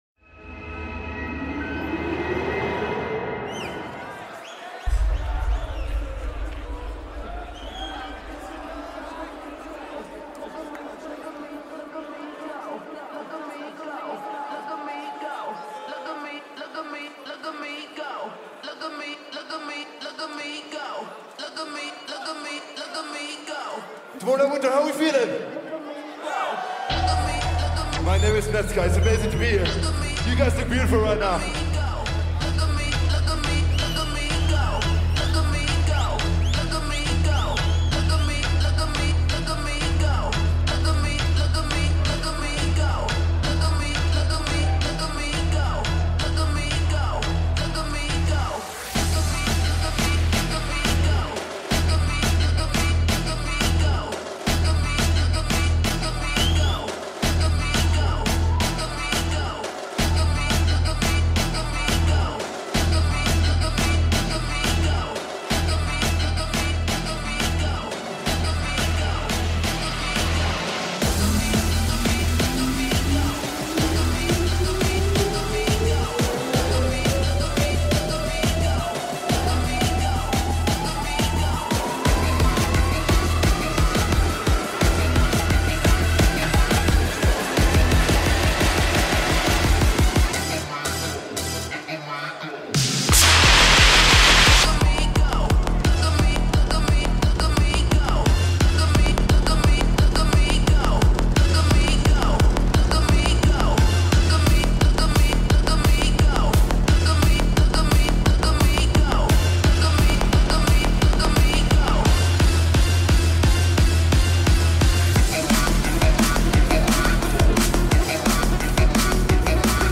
Liveset
Genre: House